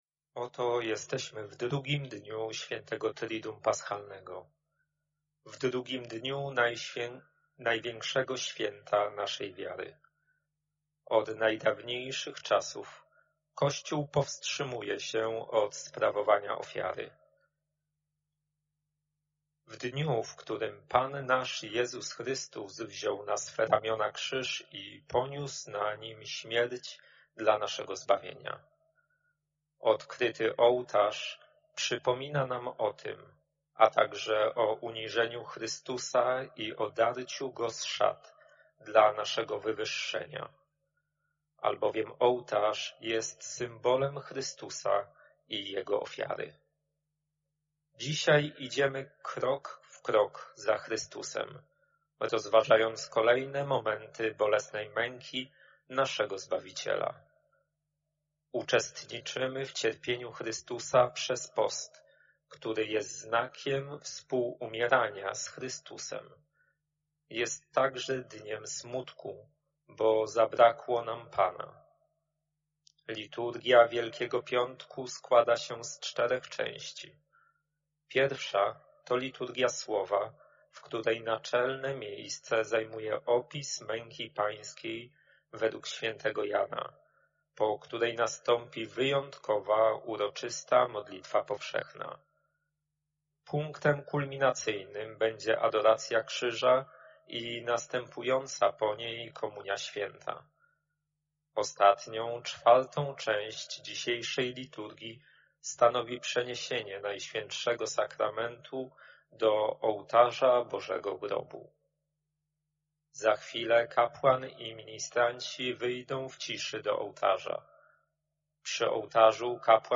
Nagrania z Triduum Paschalnego